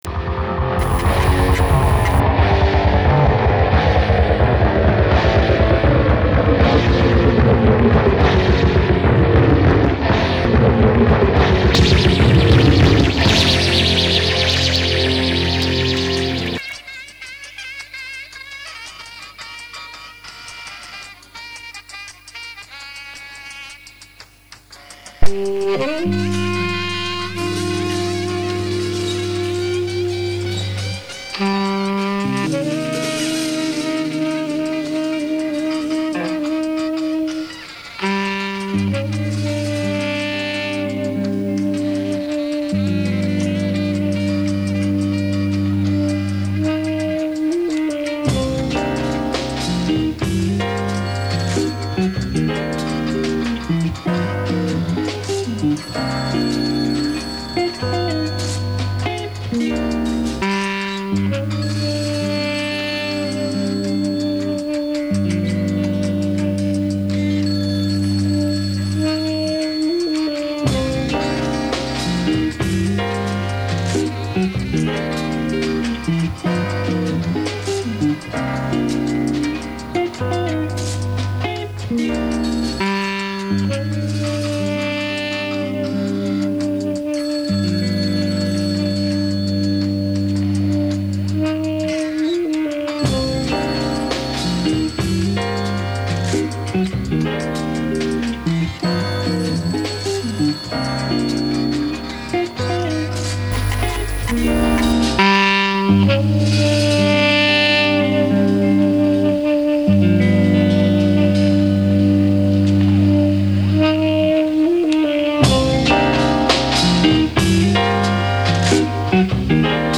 Альбом обрёл своё звучание на домашней студии